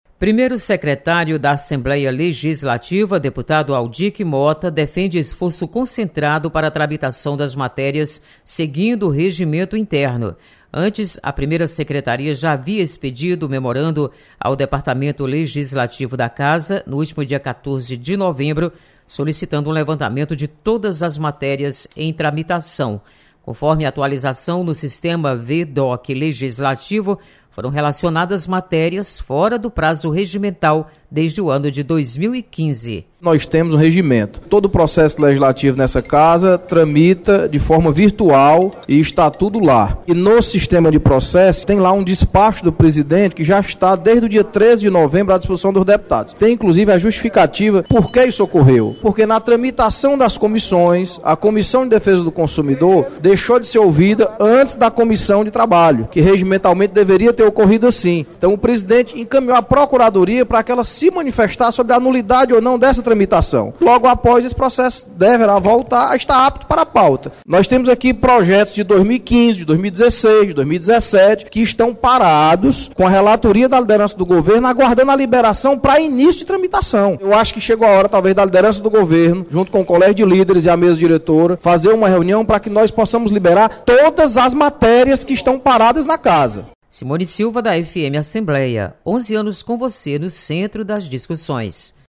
Deputado Audic Mota sugere esforço concentrado para limpar pauta de tramitação das matérias. Repórter